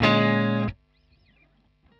Bbm7_3.wav